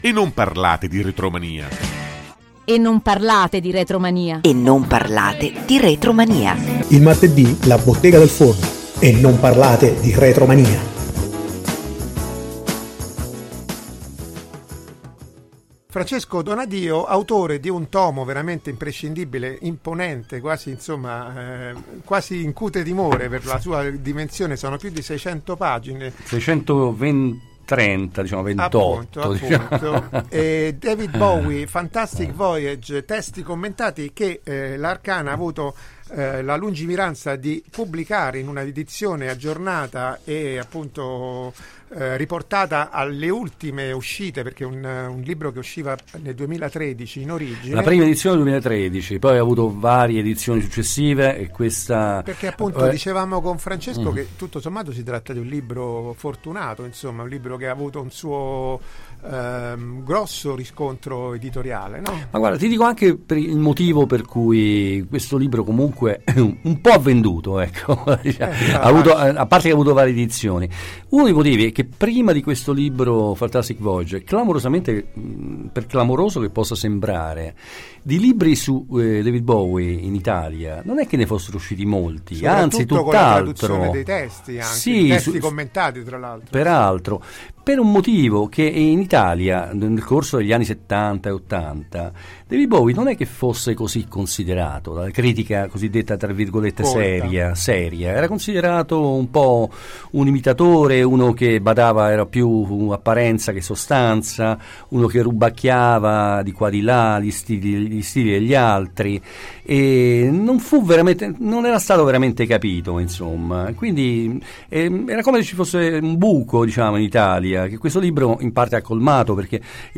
David Bowie Fantastic Voyage: intervista